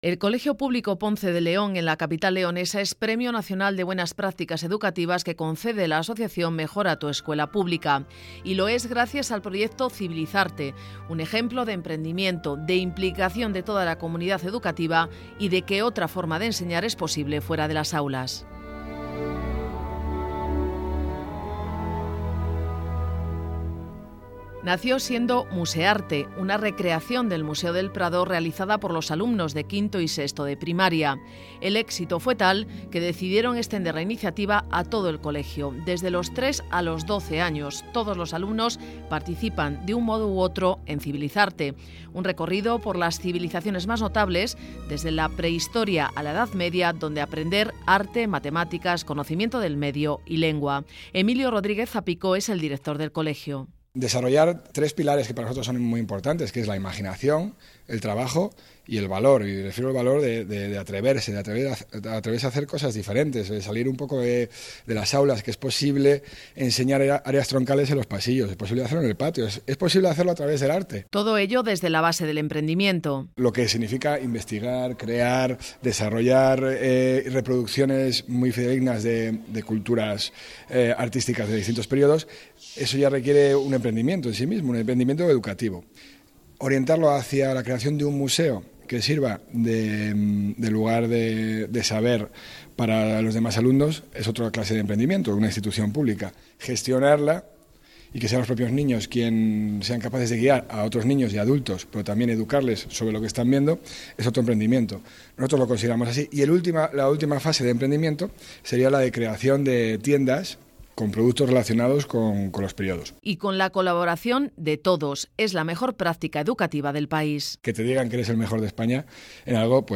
RADIO
REPORTAJE SOBRE EL PROYECTO "CIVILIZARTE"